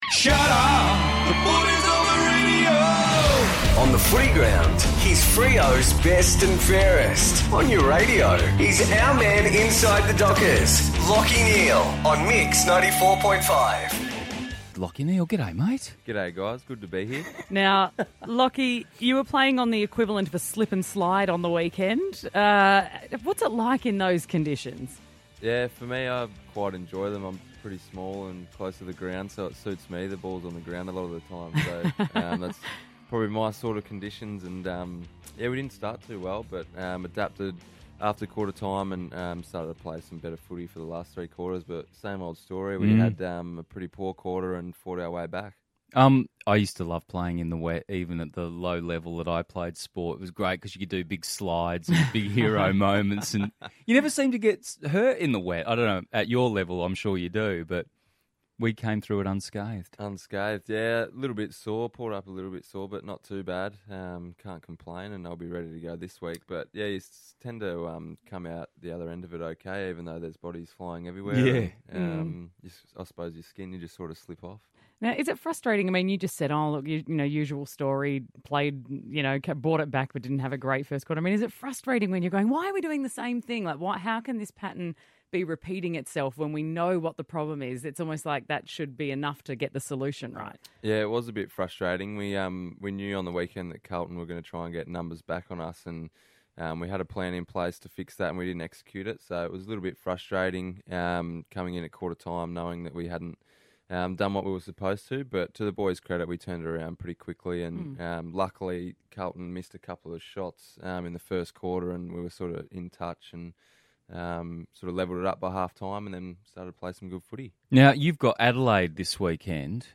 Lachie Neale chats to the team at 94.5 ahead of Freo's clash against the Crows.